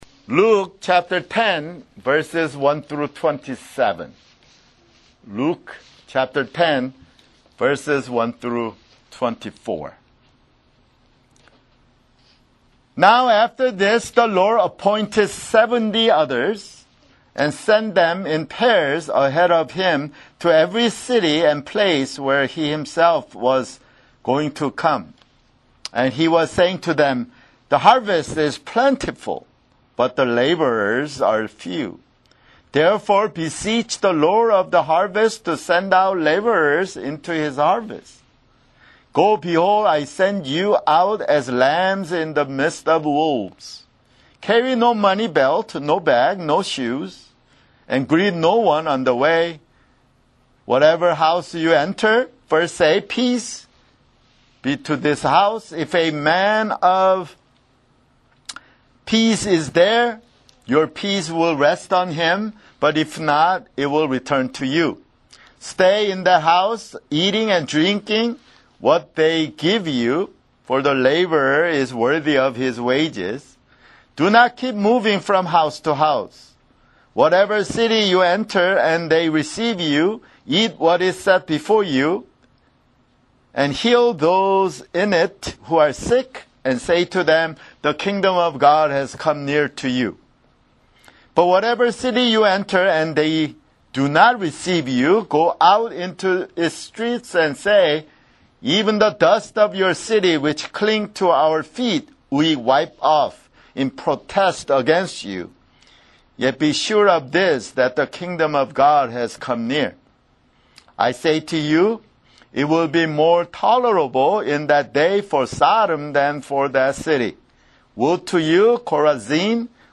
[Sermon] Luke (69)